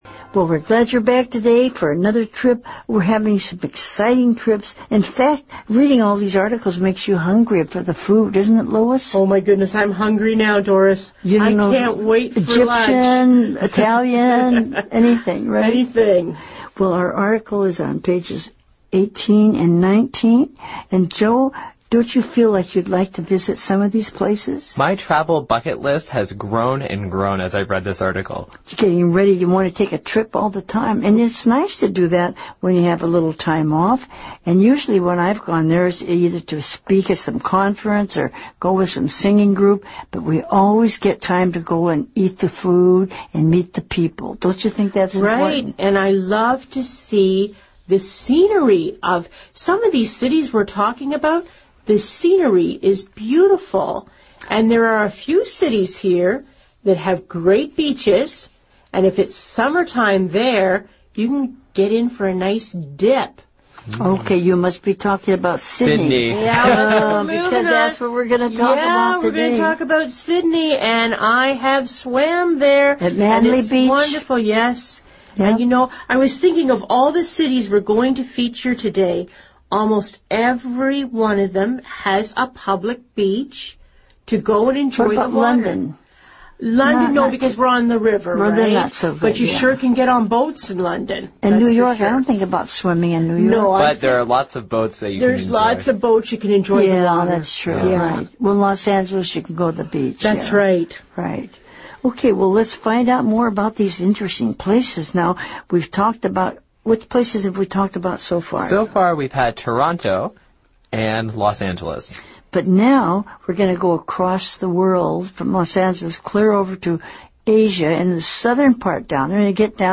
《空中英语教室》主要以收录世界最新时尚资讯、热点话题、人物、故事、文化、社会现象等为主，以谈话聊天类型为主的英语教学节目。